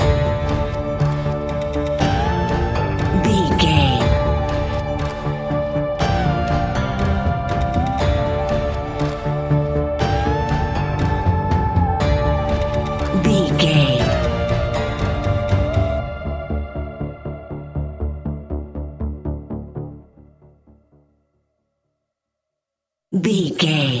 Aeolian/Minor
ominous
dark
eerie
electric guitar
drums
synthesiser
horror music